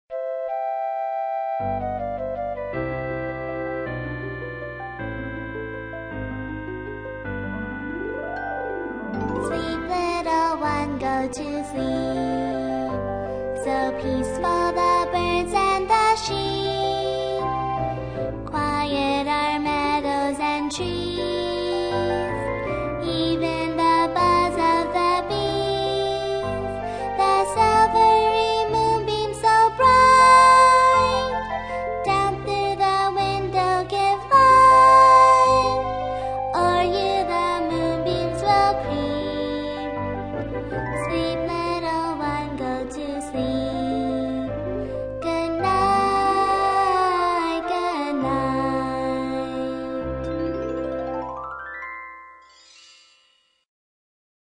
在线英语听力室英语儿歌274首 第137期:Mozart's Lullaby的听力文件下载,收录了274首发音地道纯正，音乐节奏活泼动人的英文儿歌，从小培养对英语的爱好，为以后萌娃学习更多的英语知识，打下坚实的基础。